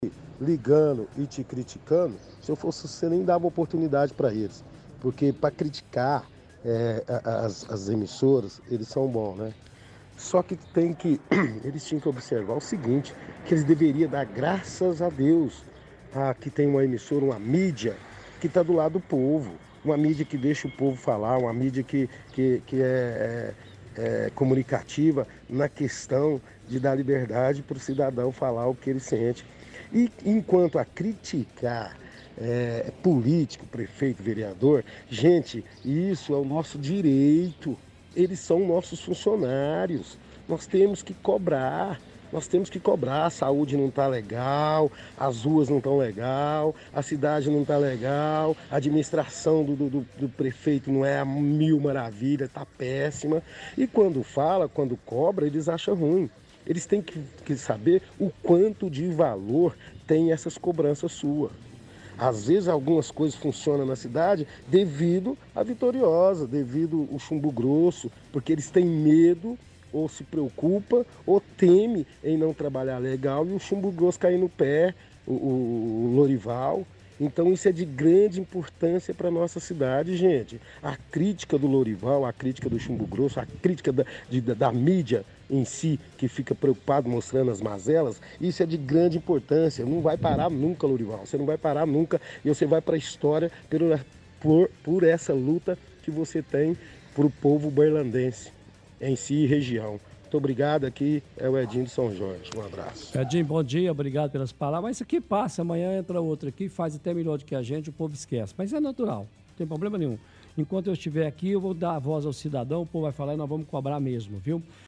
– Ouvinte defende que população tem que cobrar de políticos, porque são nossos funcionários.